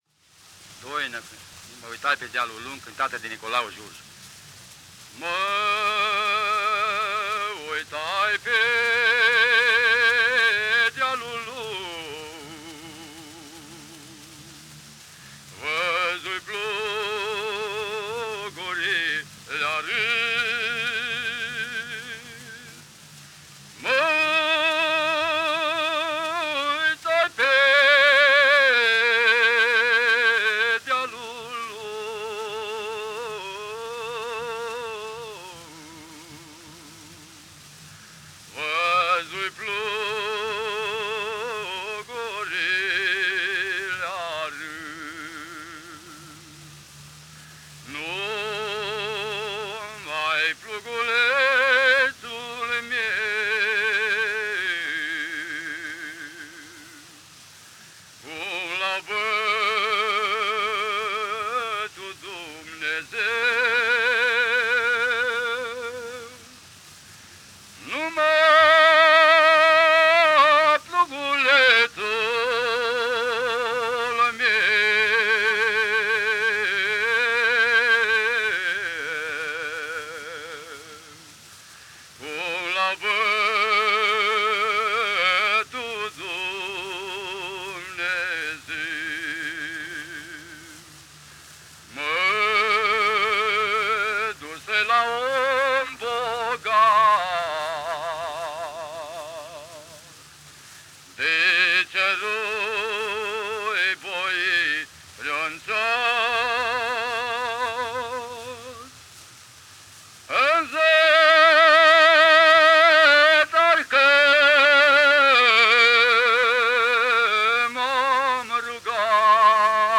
Mă uitai pe dealul lung : doina de Transylvanie [De la Sohodol de lânga Cluj.]
chant
• Chanson